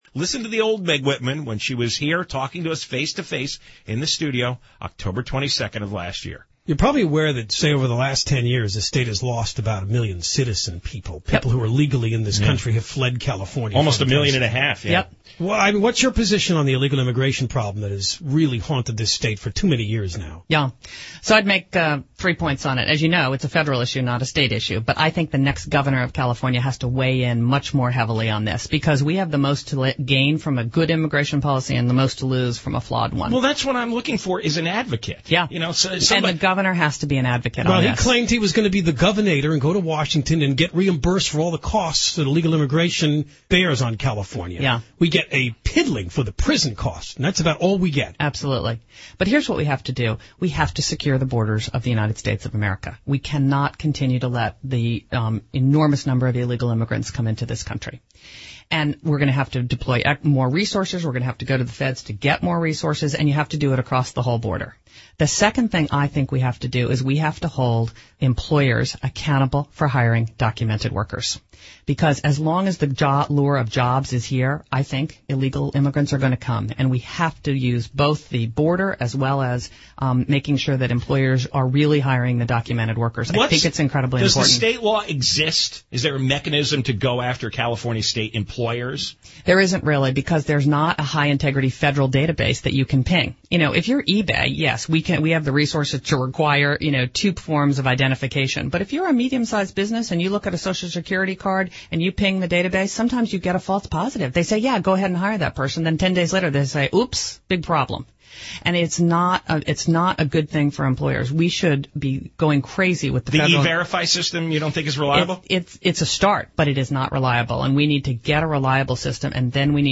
As you can imagine, John and Ken were not pleased with that characterization, especially since Whitman appeared on the John & Ken show last October to fish for votes. On that show, she discussed how sanctuary cities made her “blood boil” and how she would fight illegal immigration.